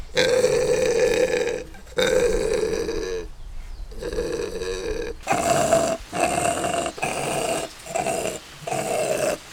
Skull of a mountain paca (Cuniculus taczanowskii), illustrating its enlarged cheek bones (at red arrow), which house resonating chambers for its vocalizations.
roar.wav